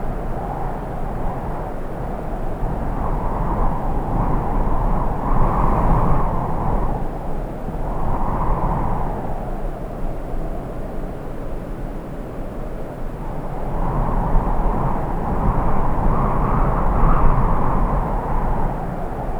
KHLOLowerWindAmb01.wav